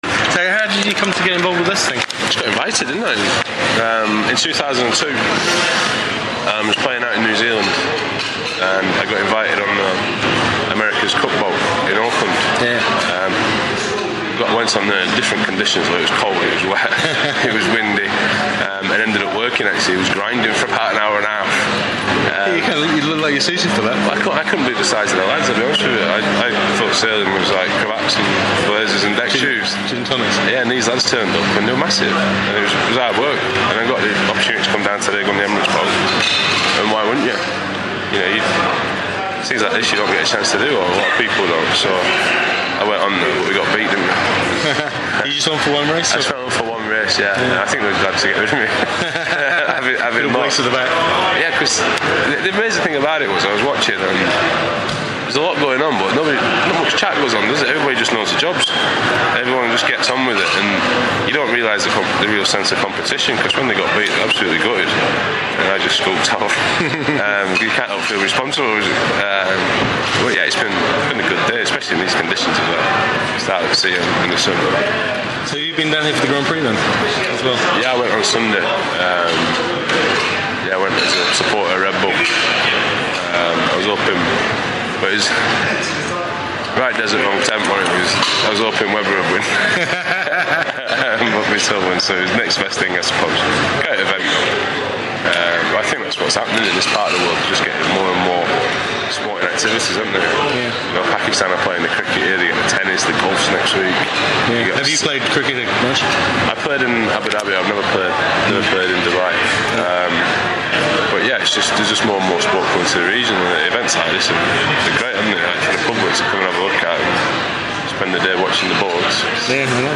Freddie Flintoff audio interview | The Daily Sail